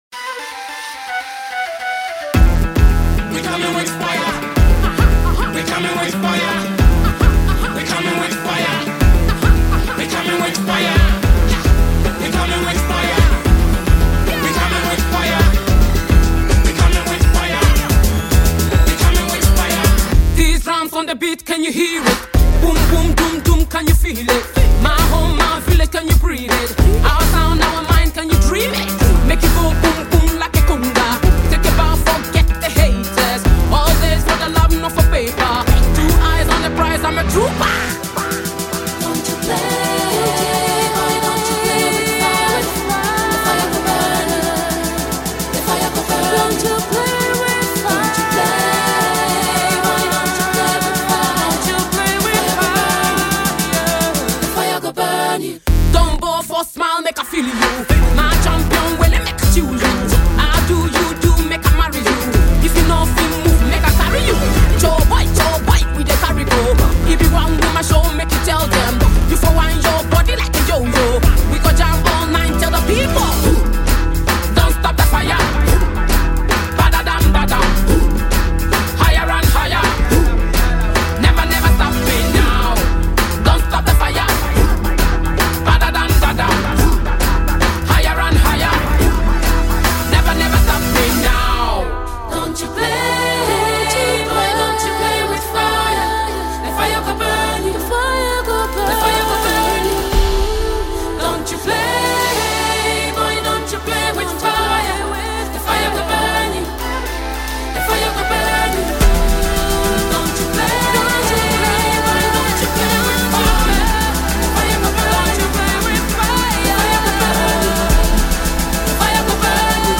Ghanaian songstress and energetic performer